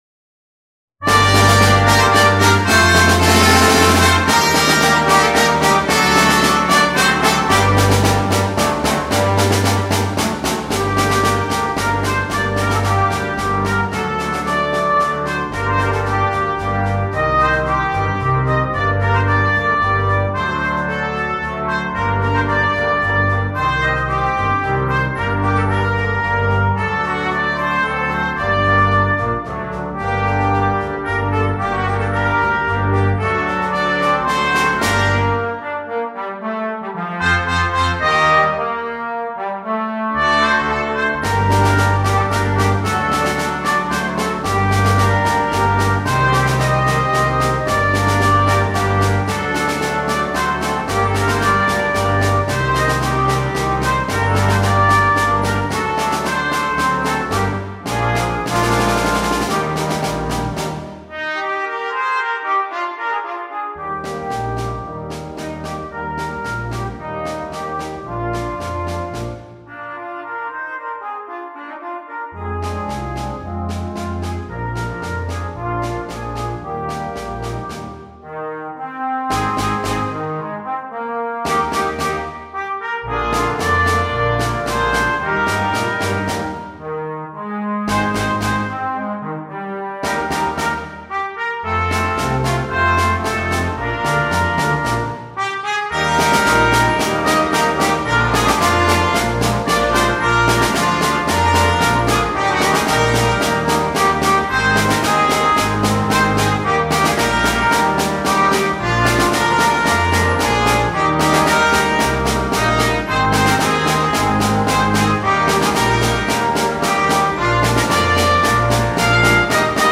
2. Jeugdorkest (flex)
8 Delen & Slagwerk
zonder solo-instrument
Lichte muziek
Part 1 in C (8va): Flute
Part 7 in C: Euphonium
Percussion